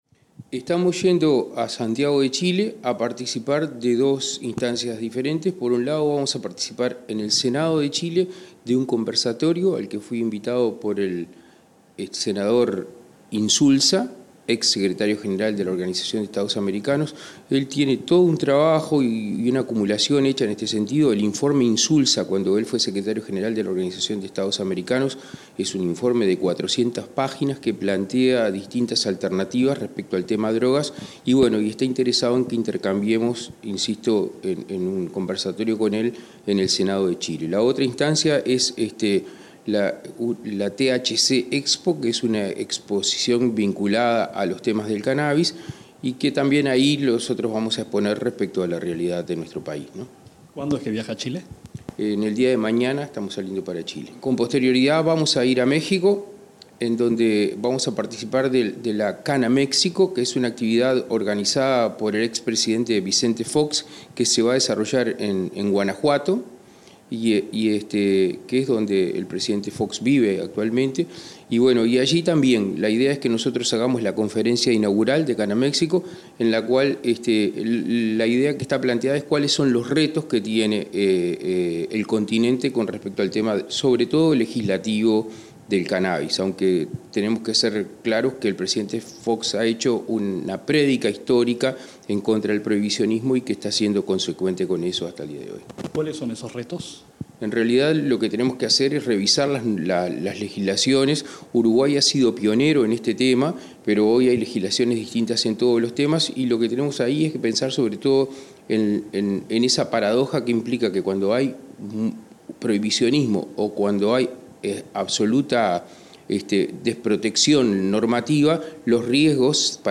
Entrevista al secretario de la Junta Nacional de Drogas, Daniel Radío